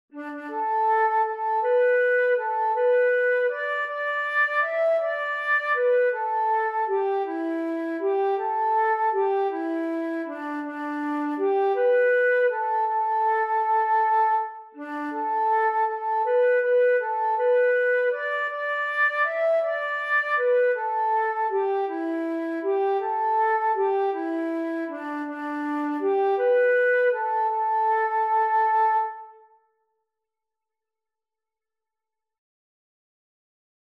Morgenlied
dit liedje is pentatonisch